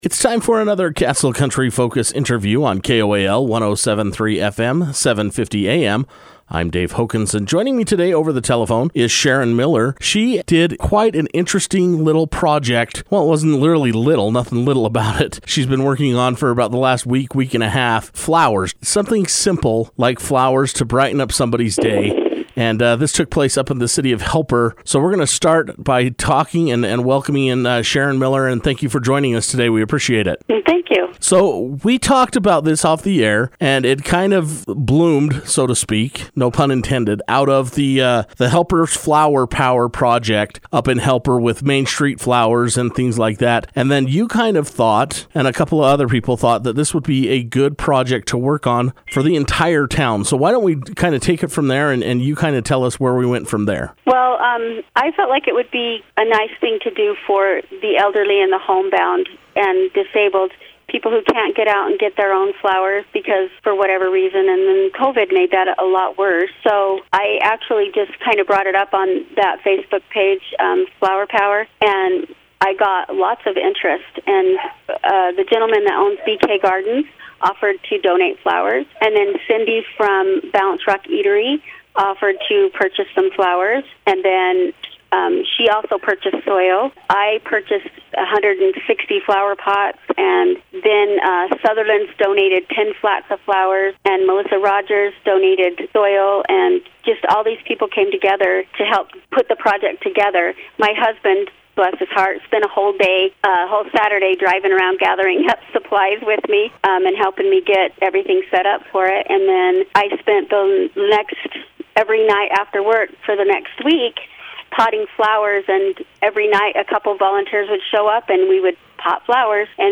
She was able to take some time to speak over the telephone with Castle Country Radio about this unique idea.